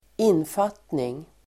Uttal: [²'in:fat:ning]
infattning.mp3